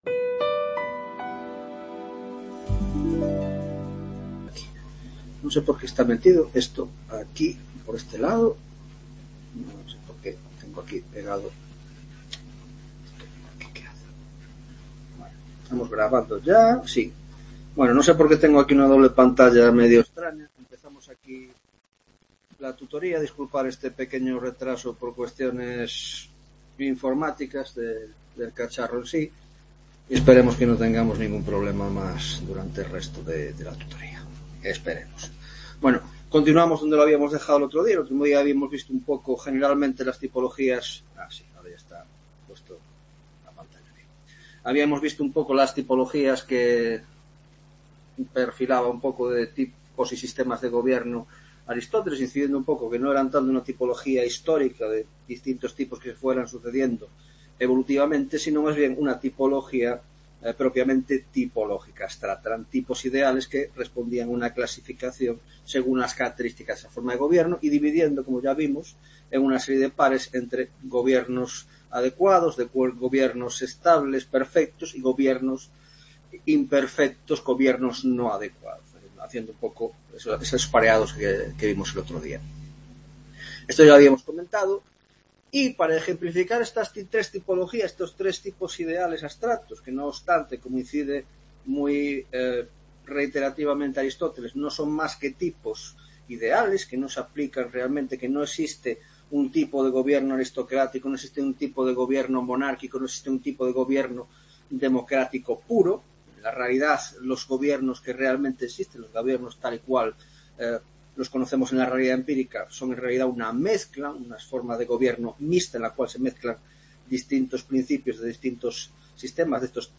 5ª Tutoria de Historia de las Ideas Políticas